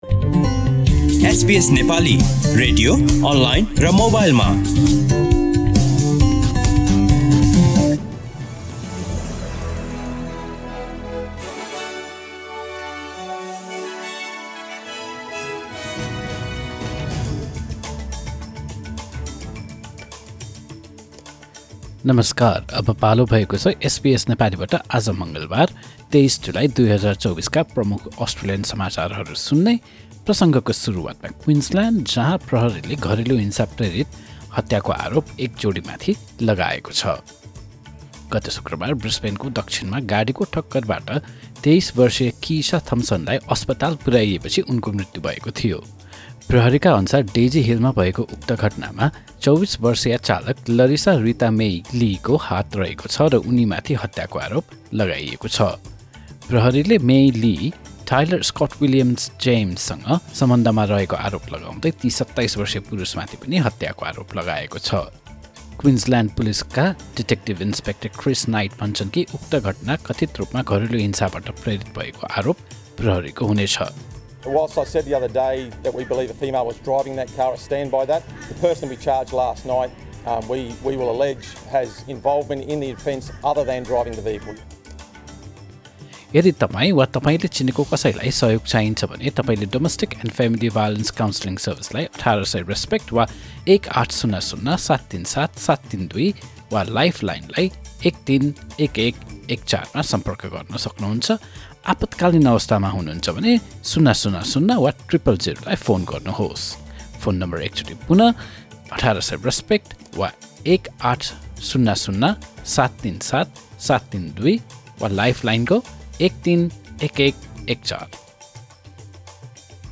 SBS Nepali Australian News Headlines: Tuesday, 23 July 2024